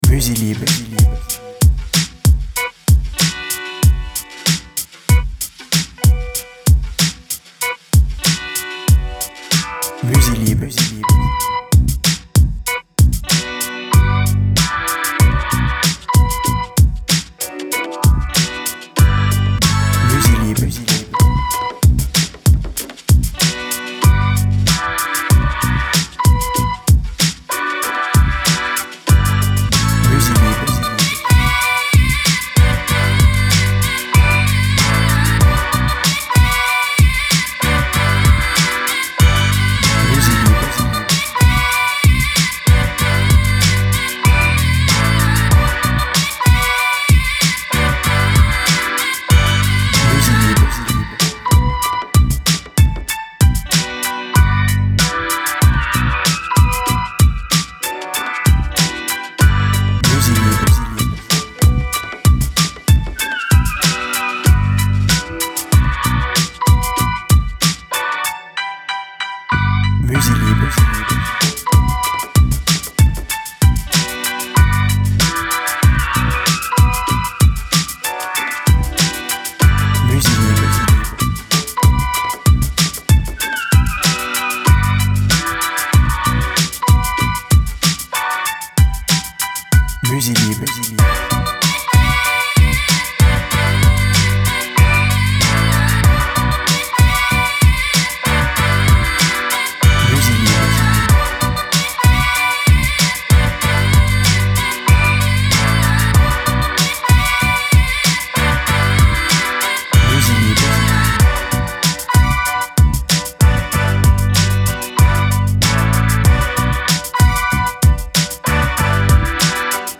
BPM Moyen